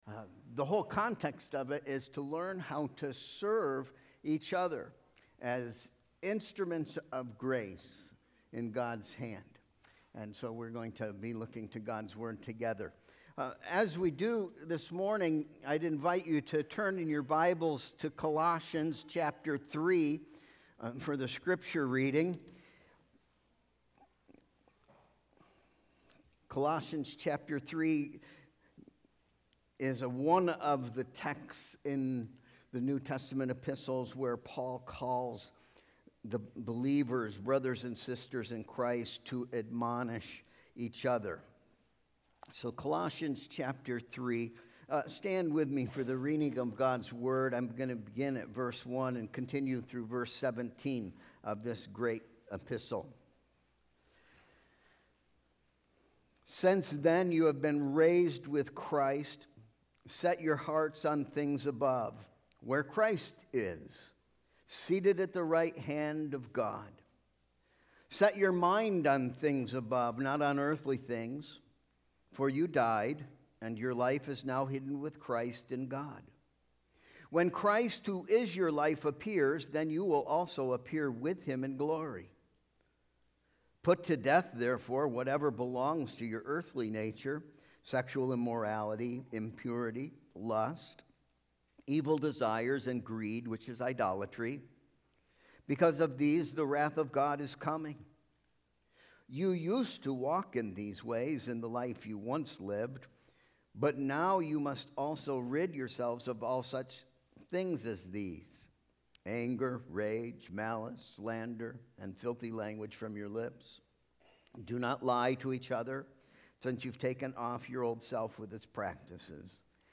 Sermon Proposition: We are called to practice admonishing each other as Spirit-empowered instruments of grace for 3 diving purposes: